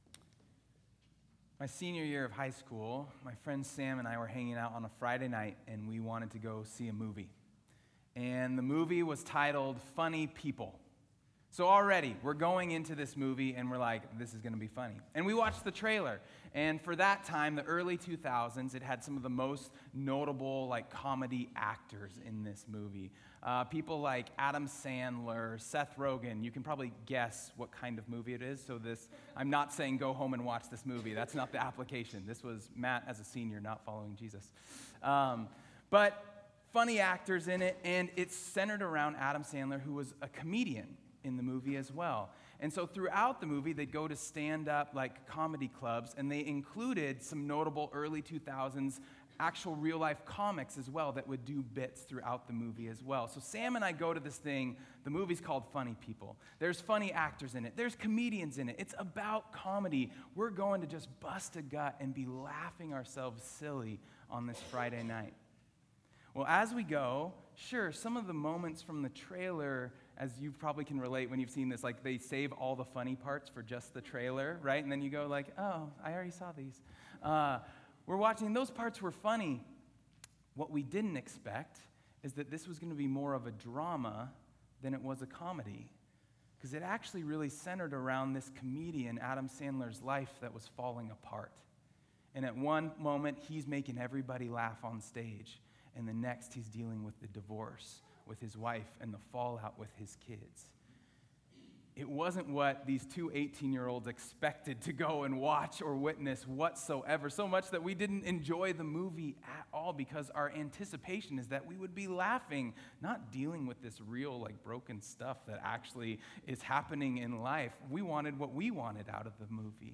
sermon-7-6-25.mp3